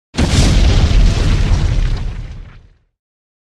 Explosion Sound Effect Free Download
Explosion